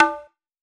Hand Tabla 01.wav